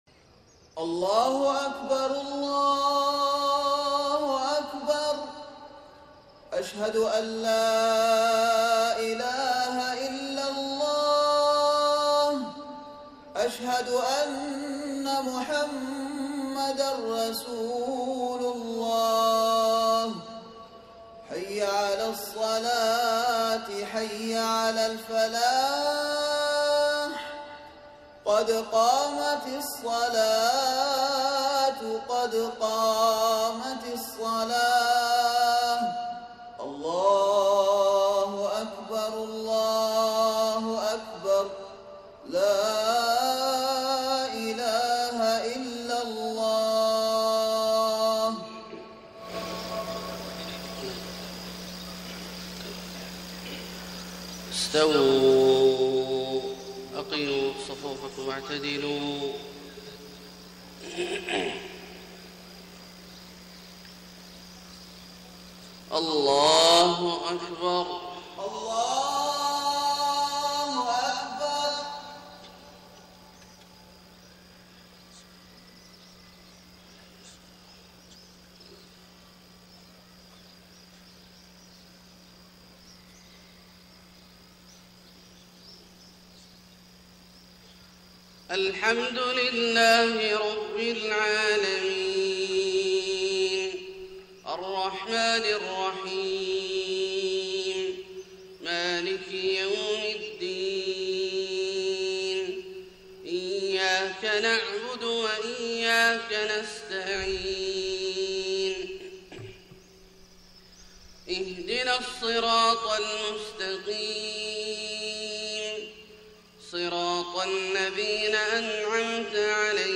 صلاة الفجر 4-3-1431هـ من سورة البقرة {204-218} > 1431 🕋 > الفروض - تلاوات الحرمين